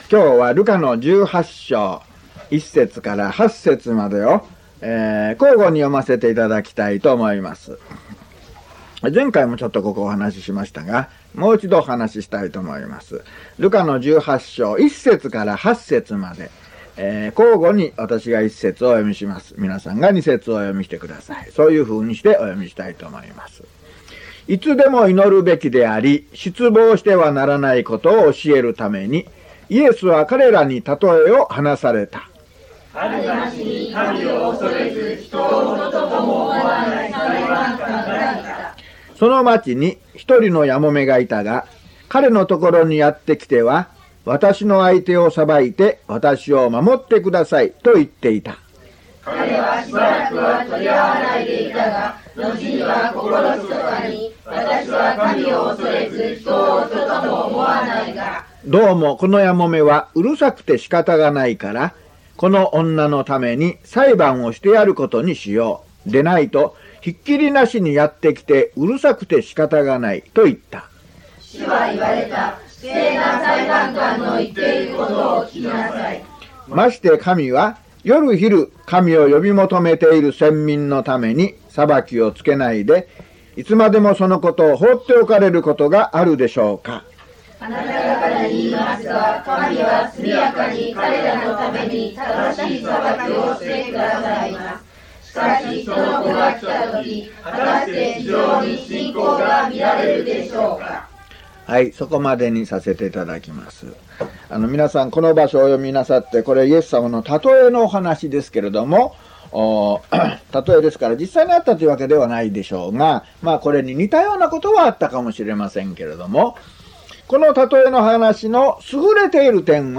luke133mono.mp3